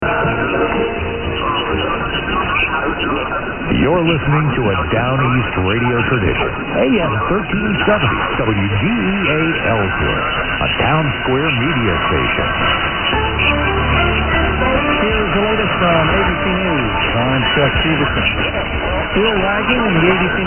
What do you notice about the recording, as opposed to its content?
After some attempts at recording at dawn, and hearing virtually nothing, despite the beverage antennas, signals are again starting to re-appear as if by magic.